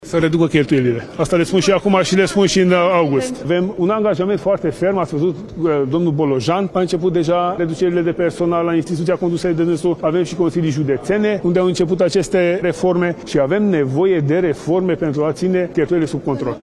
Ministrul Finanțelor, Tanczos Barna: Avem nevoie de reforme pentru a ține cheltuielile sub control